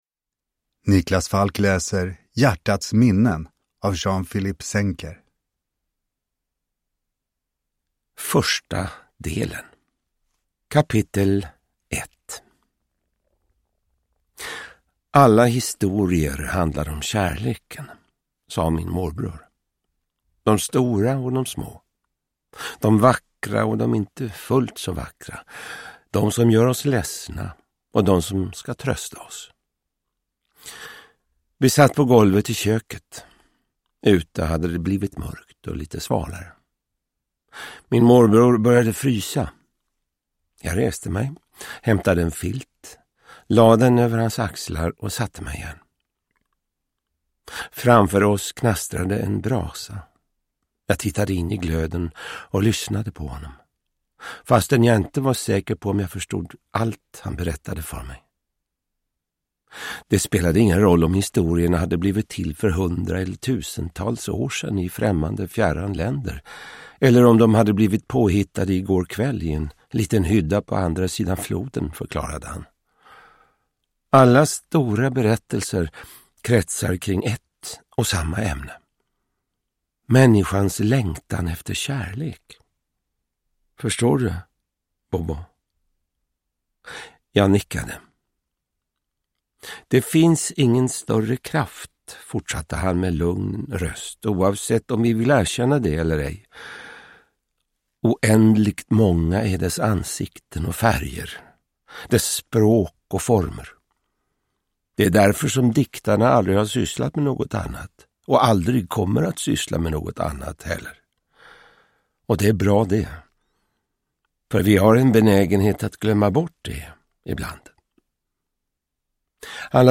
Hjärtats minnen – Ljudbok – Laddas ner